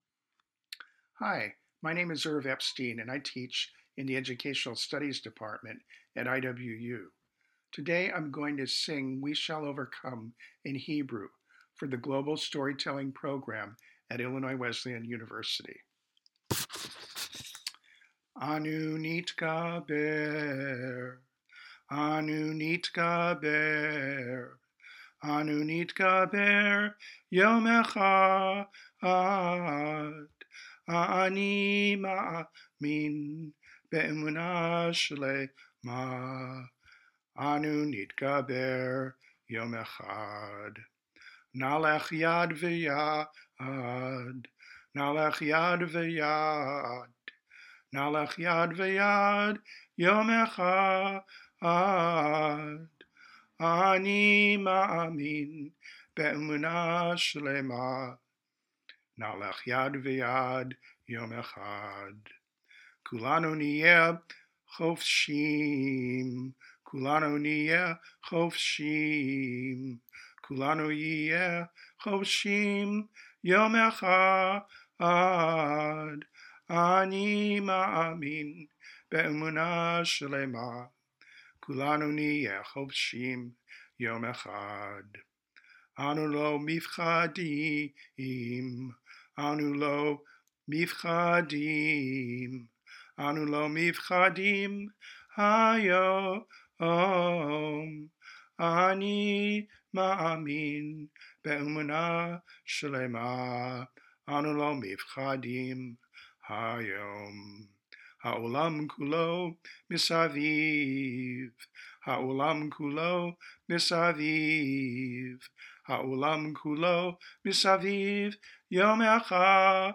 Oral History Item Type Metadata